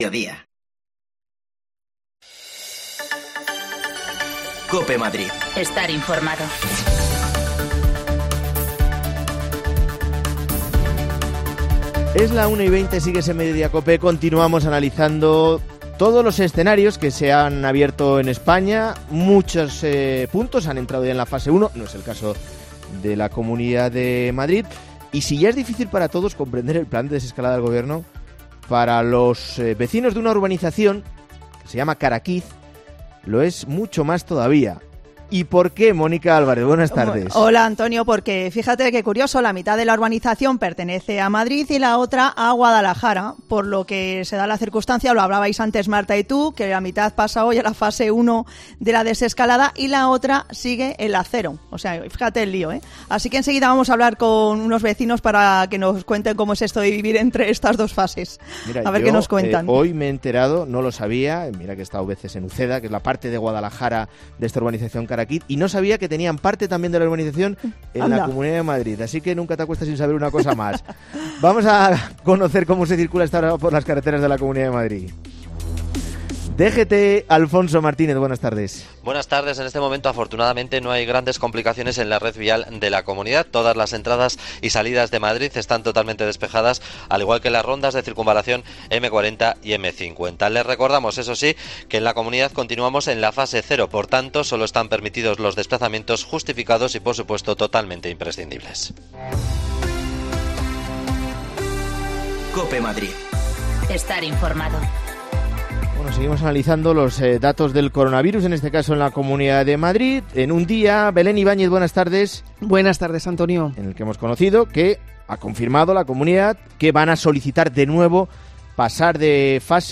AUDIO: Hablamos con vecinos que viviendo en una misma urbanización en Uceda en Guadalajara se encuentran en fases diferentes